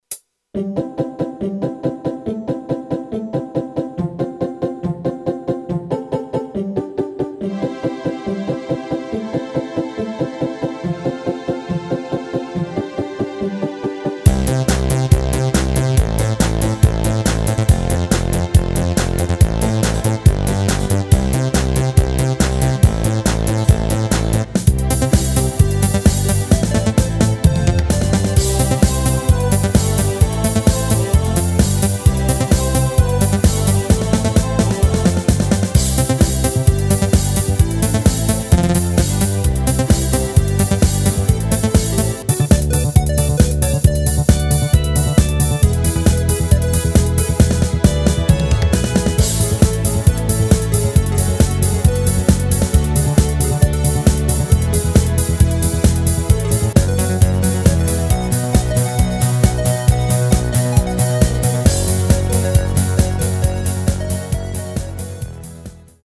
Rubrika: Pop, rock, beat
- směs
Karaoke